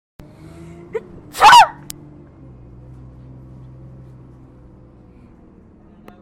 Top B Bus Sneeze for those wanting to hear one